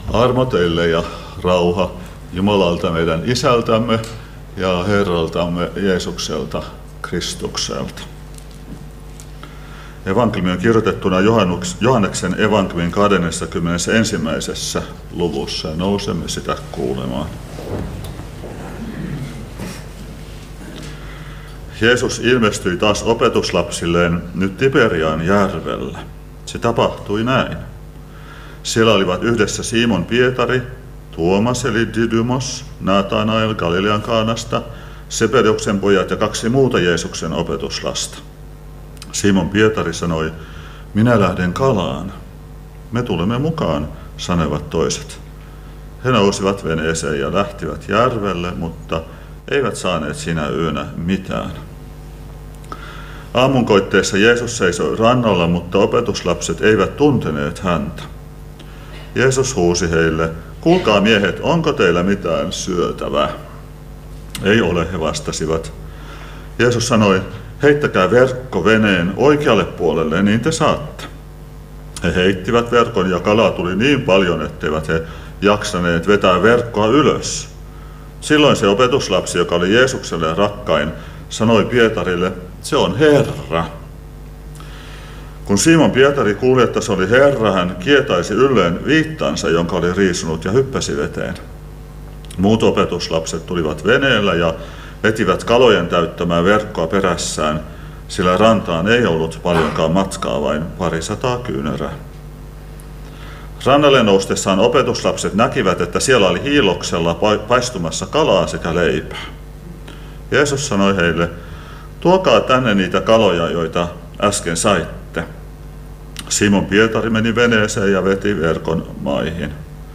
Karkku